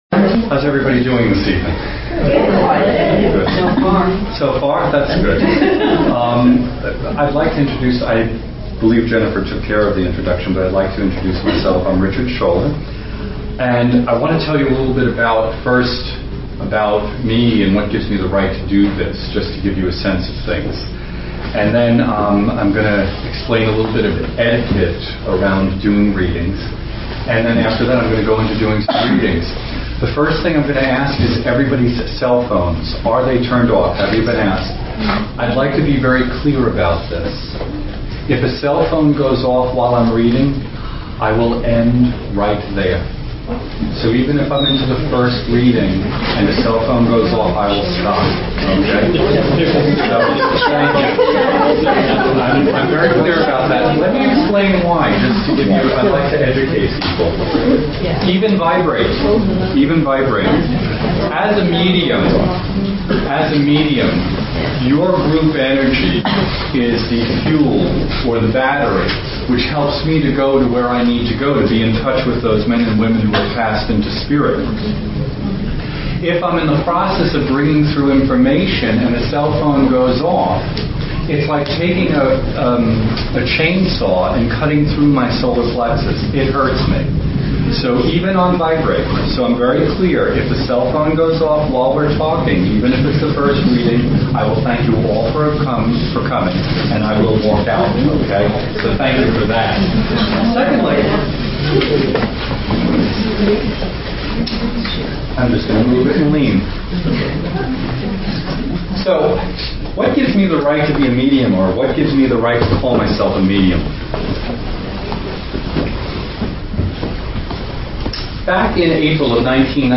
The only thing he knew about the evening was that he would be giving a gallery reading to about 50 people.